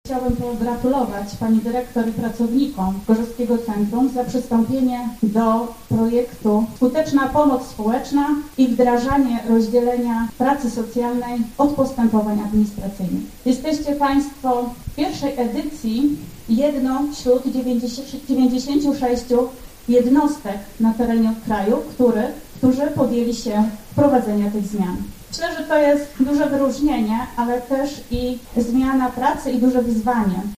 Za zaangażowanie, empatię i codzienną pomoc, dziękowano dziś pracownikom socjalnym GCPR-u. W auli Akademii im. Jakuba z Paradyża odbyły się dziś uroczystości związane z Dniem Pracownika Socjalnego.